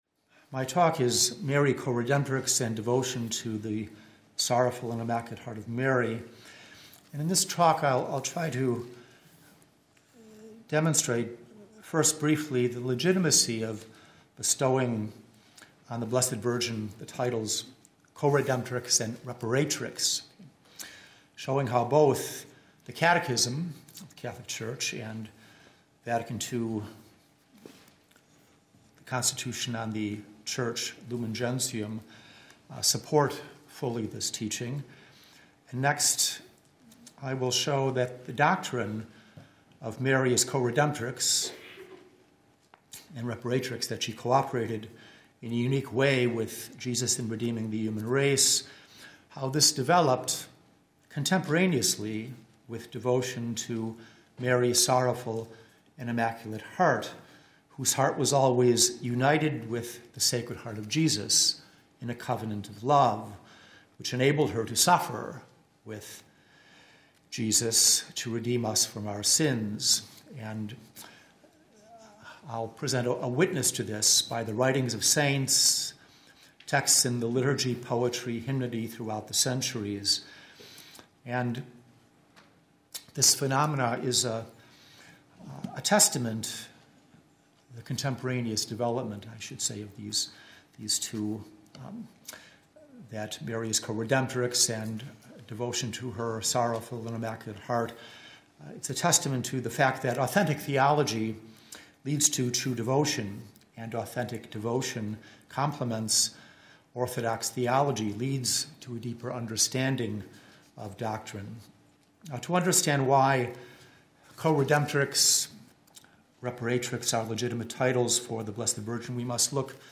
talk
at the Marian Coredemption symposium at the Shrine of Our Lady of Guadalupe, La Crosse, WI in 2018